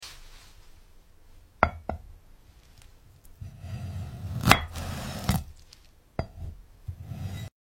ASMR Slicing a Glass Orange🎧🍊 sound effects free download